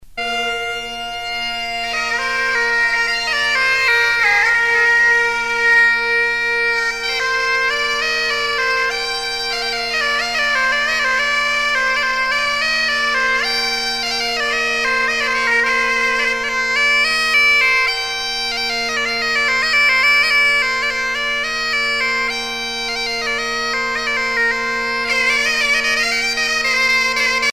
danse : an dro